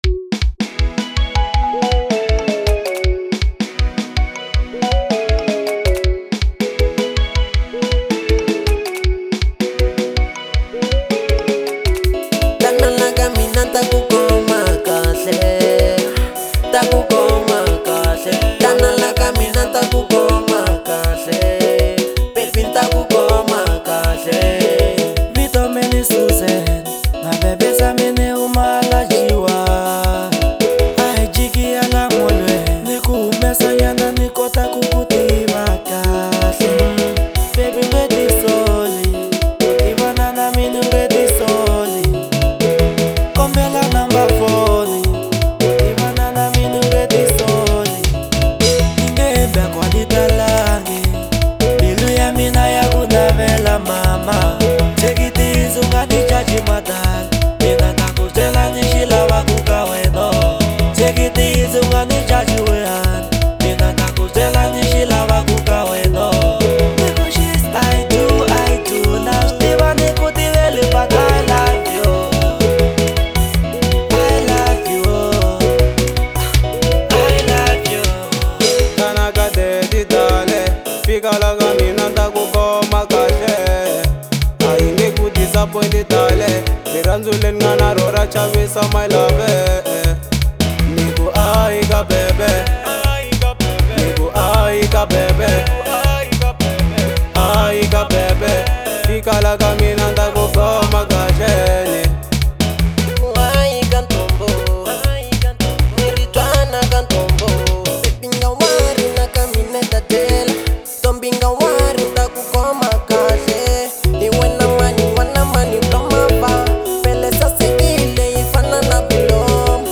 04:29 Genre : Xitsonga Size